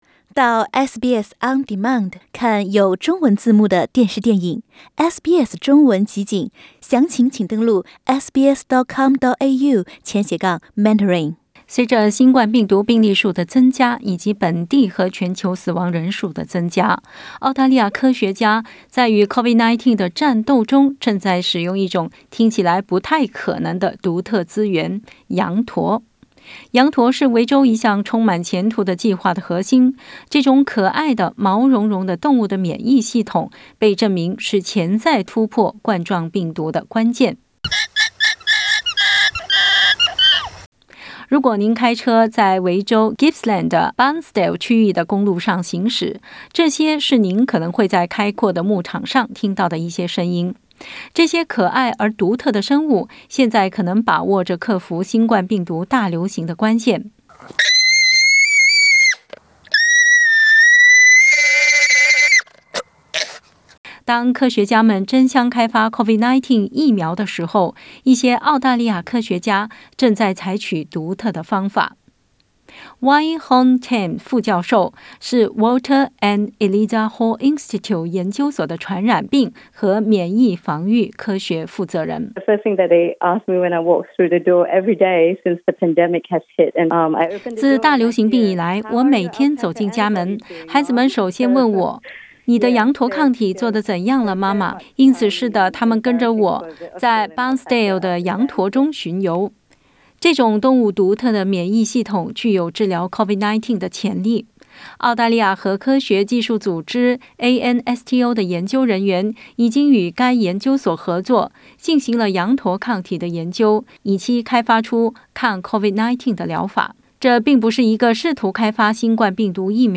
點擊圖片收聽詳細報道。